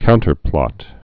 (kountər-plŏt)